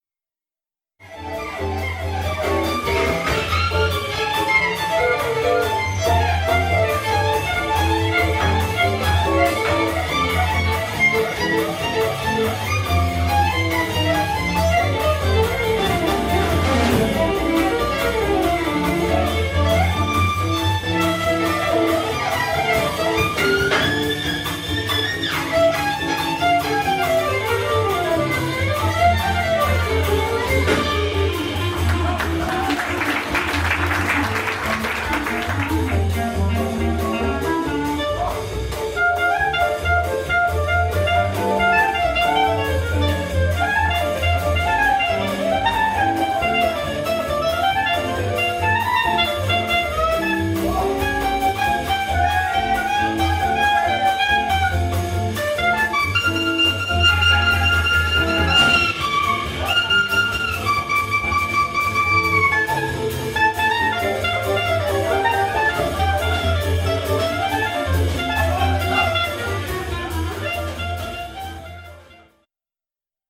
violon, piano, chant
clarinette
guitare
contrebasse
batterie